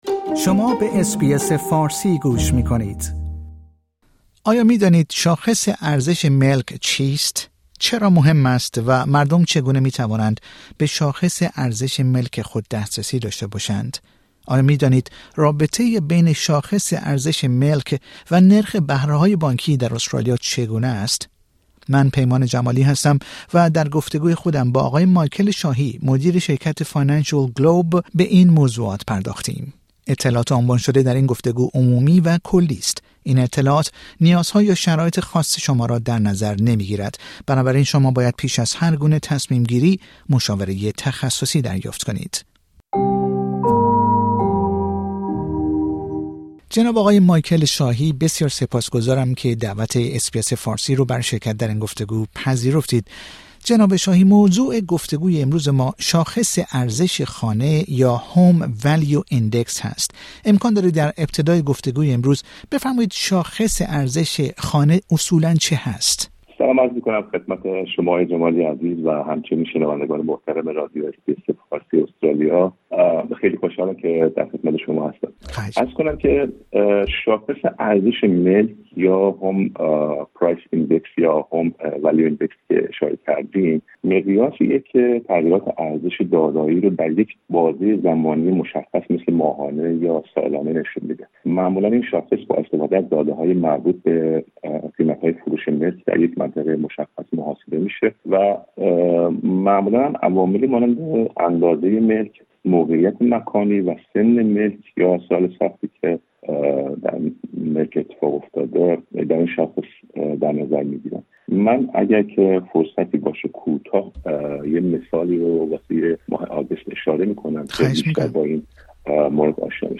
در گفتگو با رادیو اس بی اس فارسی به پرسش ها پاسخ می دهد